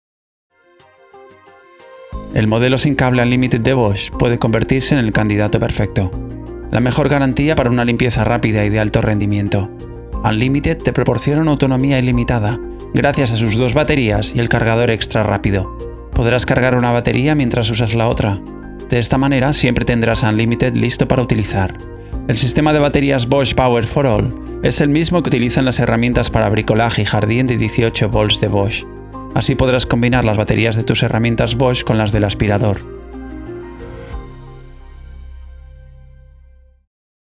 Excellent quality, Speed, Versatile, Young voice - Middle age Excelente calidad, Rapidez, Versátil, Voz joven - Media edad
Sprechprobe: Industrie (Muttersprache):
Large diaphragm microphones for voice, Dynaudio near-field listening, Sound Devices preamp, Beyerdynamic headphones, Mackie Onyx table, and acoustically conditioned room